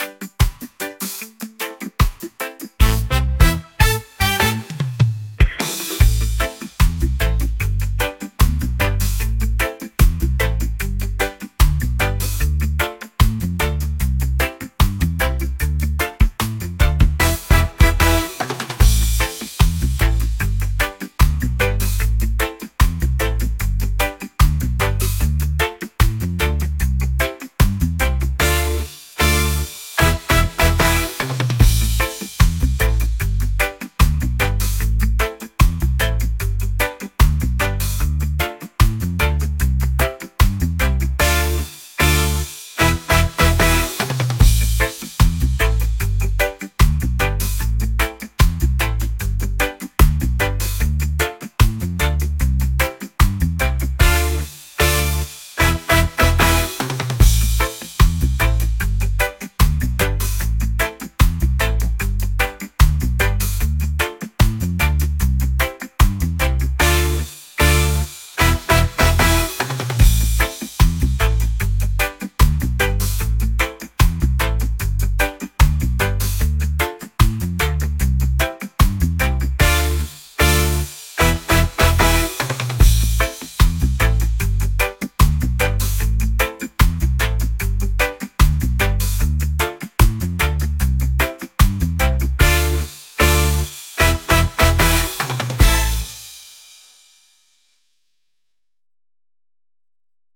reggae | pop | soul & rnb